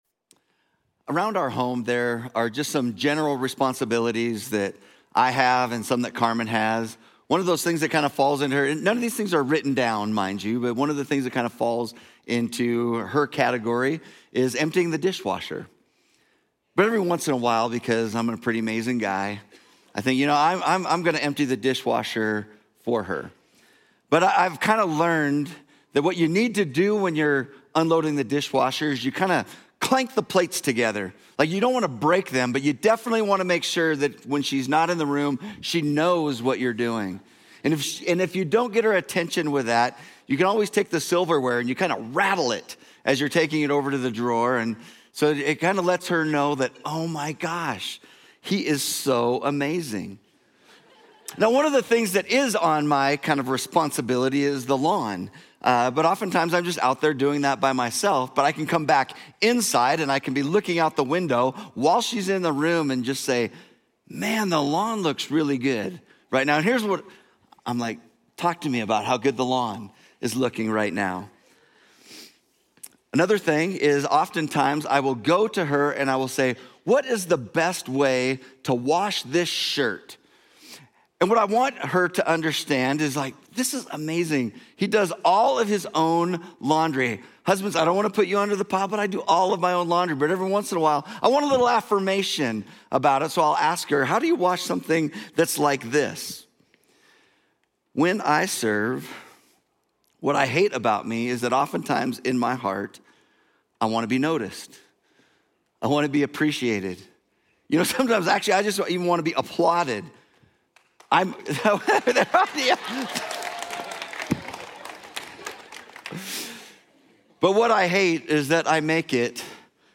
Journey Church Bozeman Sermons Bless: Serve May 25 2025 | 00:44:17 1x 00:00 / 00:44:17 Subscribe Share Apple Podcasts Overcast RSS Feed Share Link Embed